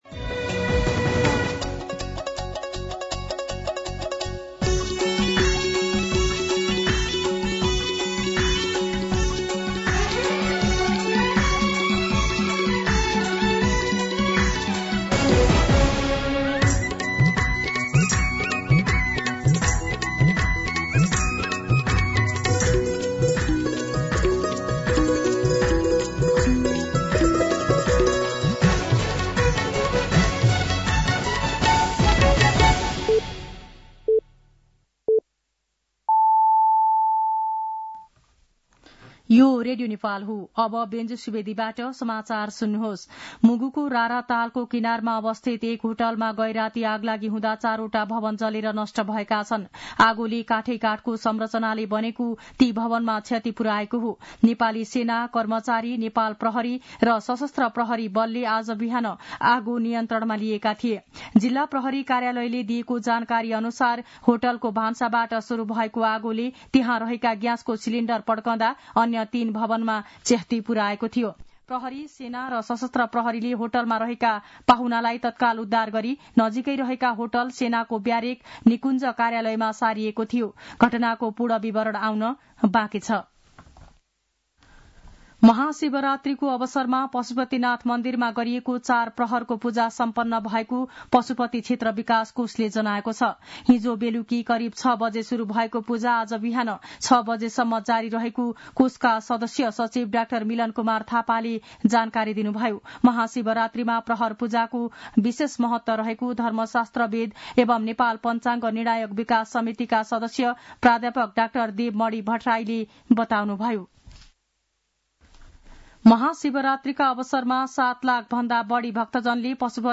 मध्यान्ह १२ बजेको नेपाली समाचार : १६ फागुन , २०८१